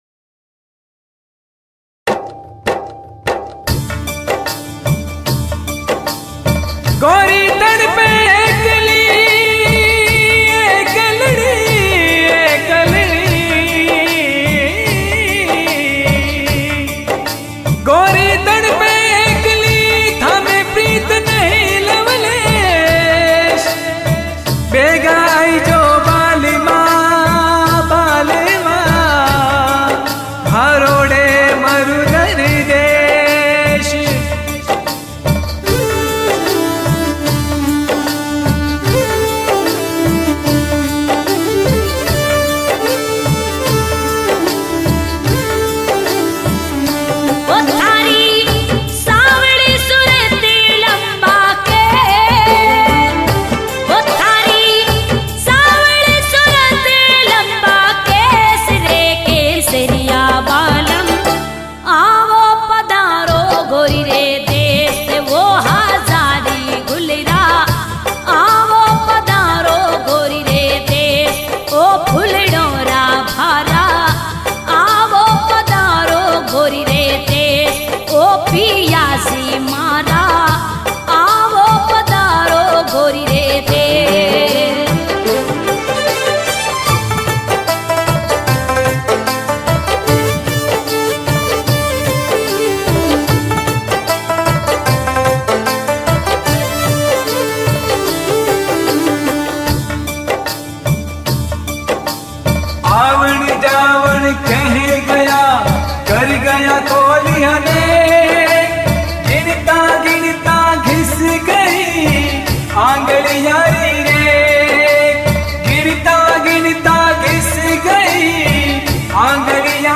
Rajasthani Folk Song